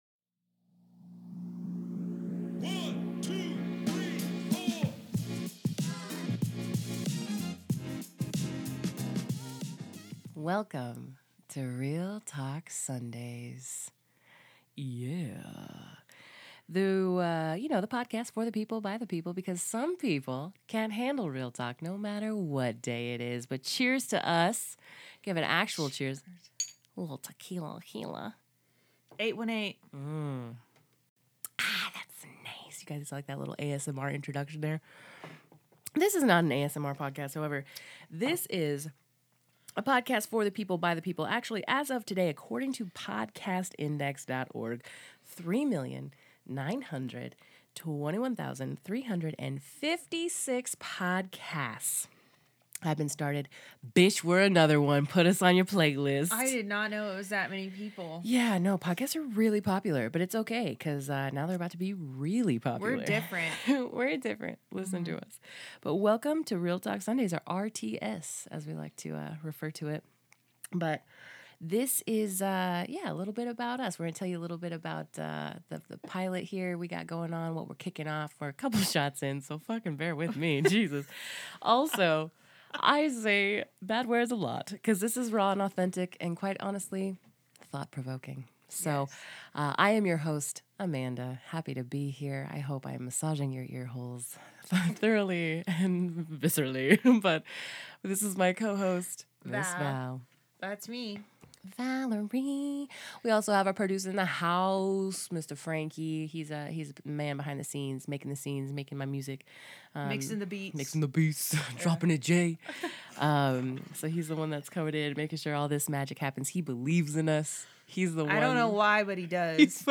At the end of the day, this podcast is about communication and connection for friends and those who enjoy authentic conversations and organic humor.